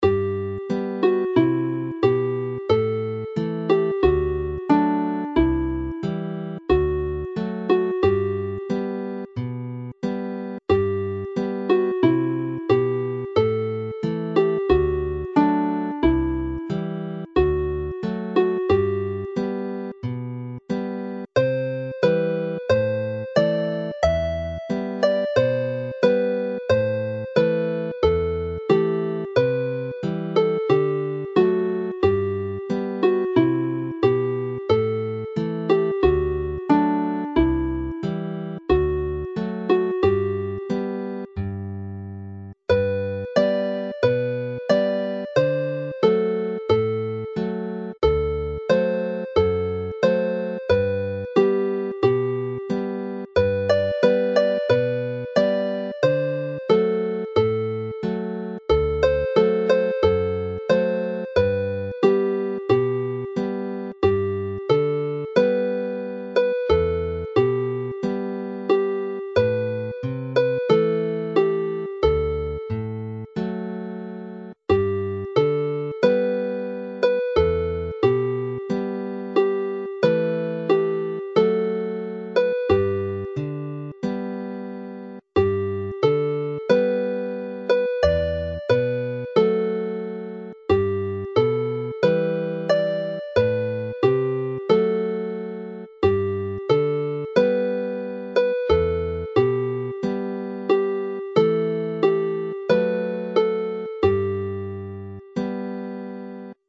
This is a good set for beginners, with lullaby-paced, cradle-rocking tunes that are easy to play.